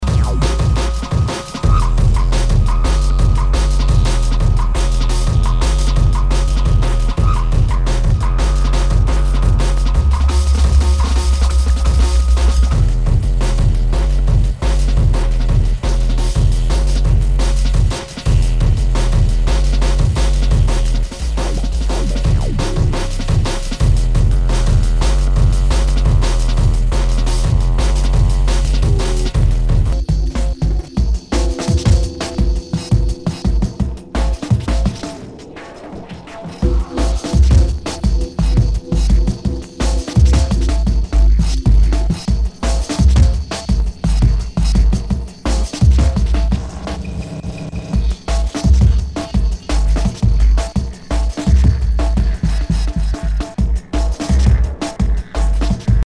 Nu- Jazz/BREAK BEATS
ナイス！ドラムンベース！
盤に傷あり全体にチリノイズが入ります。